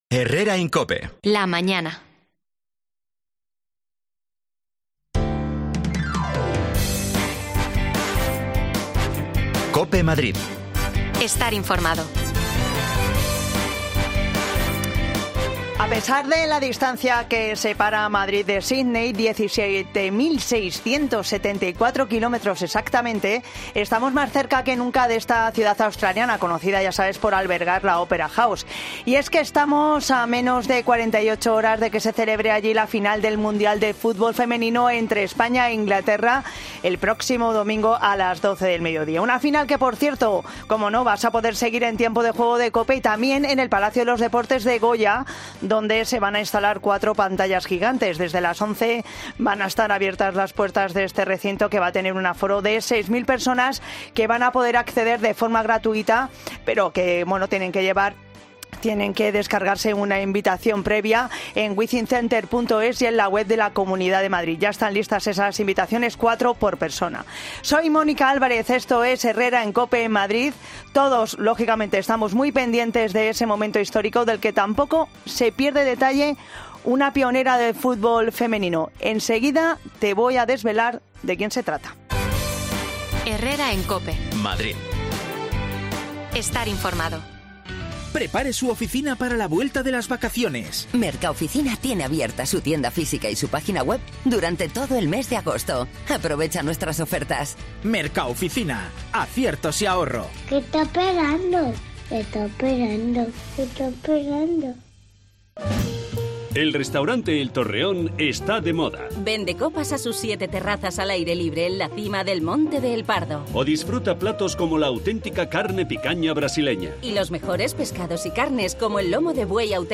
El futbol femenino está a 48h de la gran final. El Wizink Center prepara ya pantallas para verlo el domingo y mientras se preparan hablamos con una pionera de este deporte
Las desconexiones locales de Madrid son espacios de 10 minutos de duración que se emiten en COPE, de lunes a viernes.